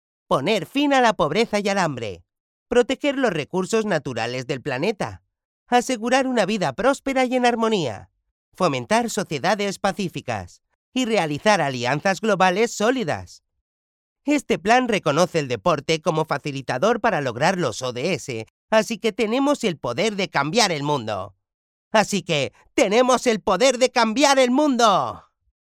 locutor español